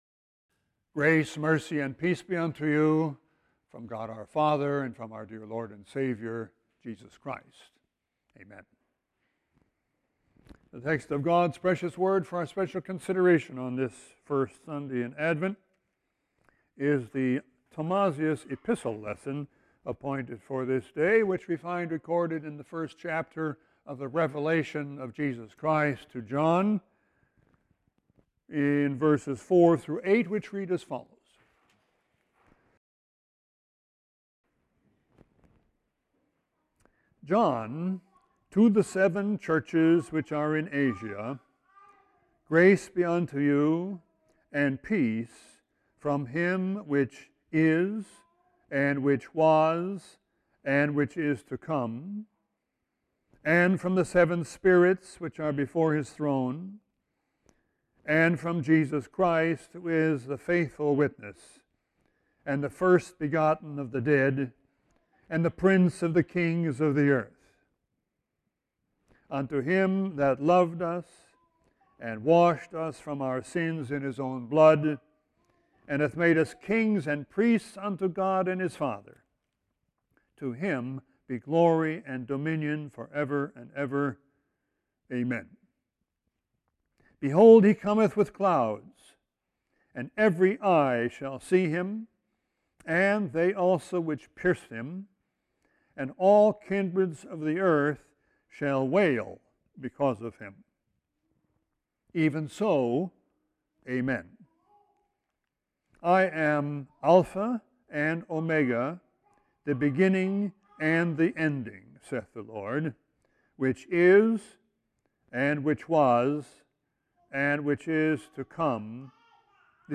Sermon 12-3-17.mp3